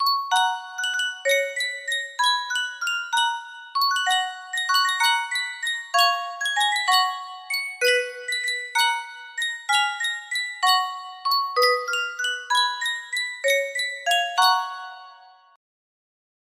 Sankyo Music Box - The Old Woman Who Lived In a Shoe Y- music box melody
Full range 60